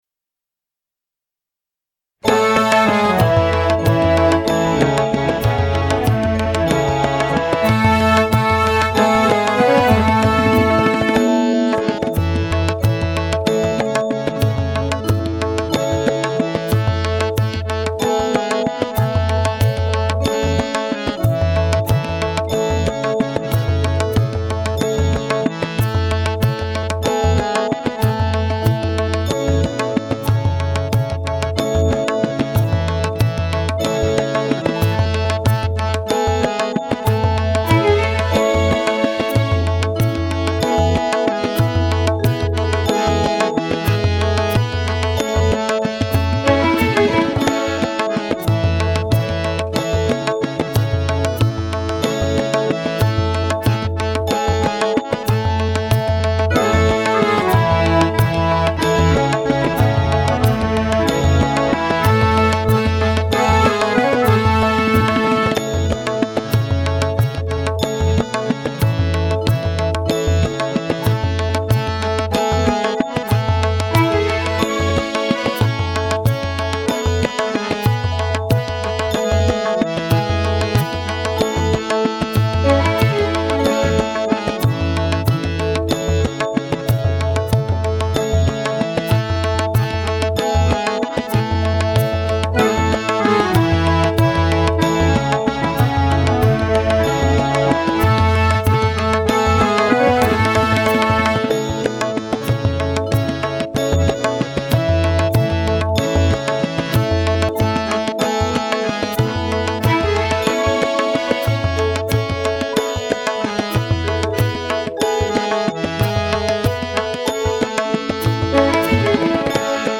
Instrumental Audio MP3